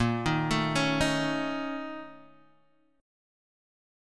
BbmM7bb5 chord